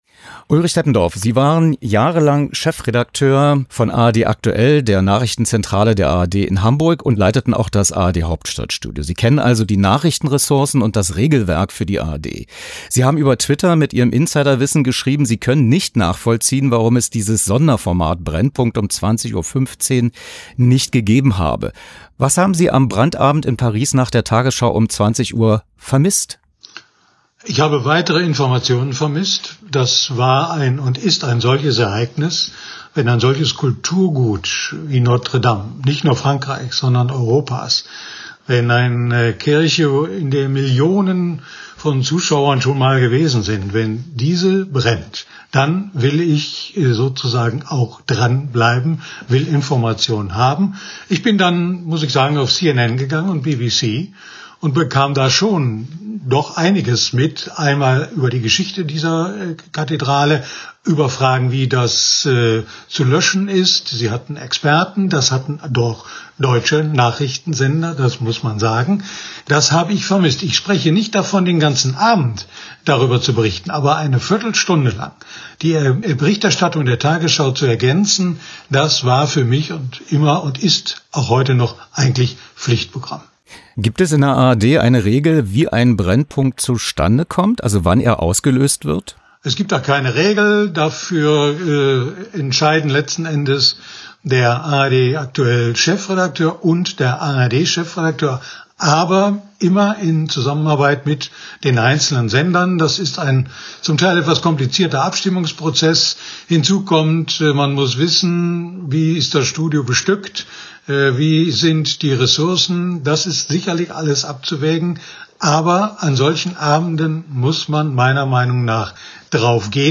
Was: Interview zur aktuellen Berichterstattung der ARD am Abend des 15.04.2019 über den Brand der Kathedrale Notre-Dame de Paris